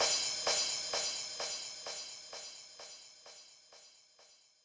INT Crash Delays.wav